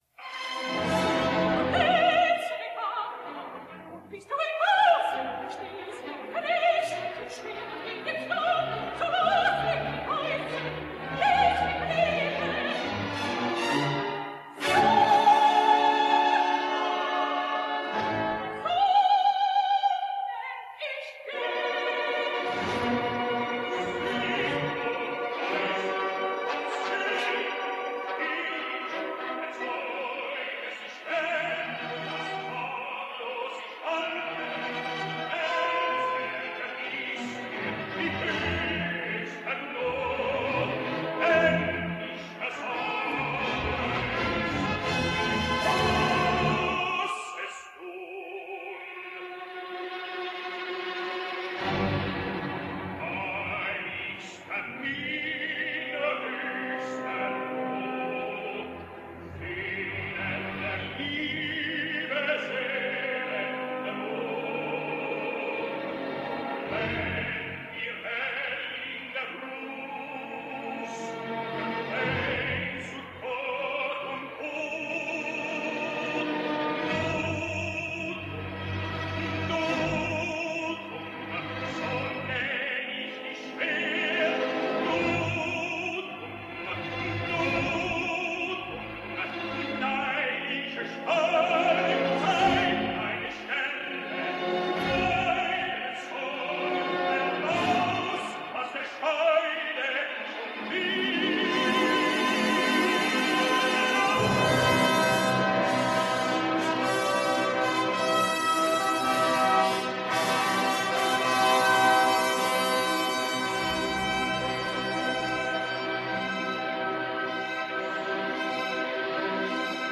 He was able to achieve an atmosphere on stage, worthy of Wagner’s white-hot trumpet calls in the orchestra pit.
Jon Vickers partner, this time by the Dutch soprano Ray Braunstein carries all before him in Wagners, Devall Curah.